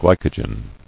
[gly·co·gen]